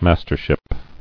[mas·ter·ship]